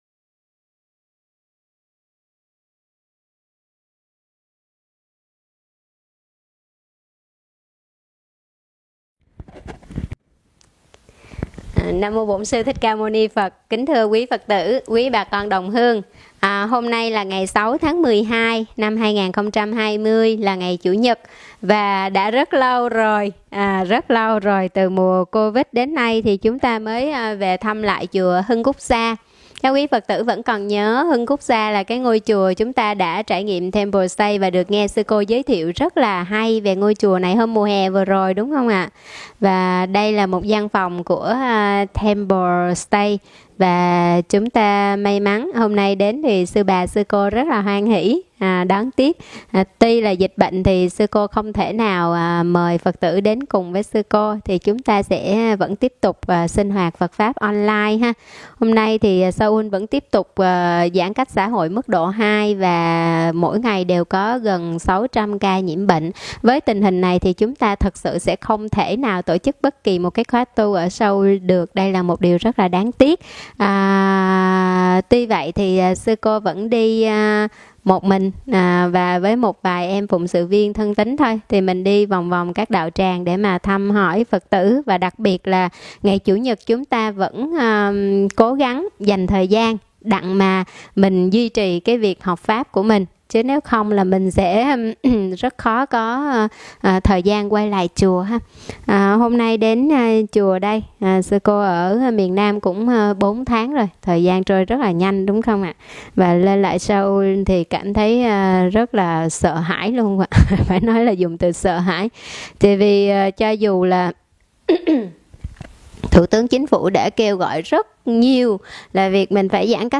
Mời quý phật tử nghe mp3 thuyết pháp Sundari Nanda